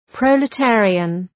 {,prəʋlə’teərıən}